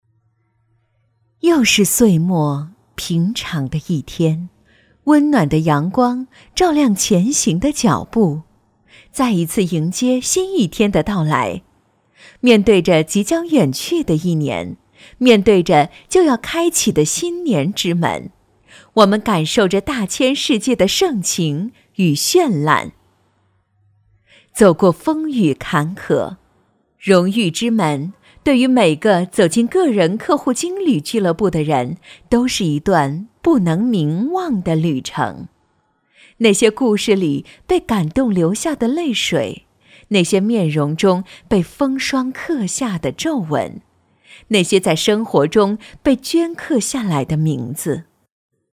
• 女S12 国语 女声 旁白-年会颁奖旁白-企业年终回顾-平安人寿 娓娓道来|调性走心|亲切甜美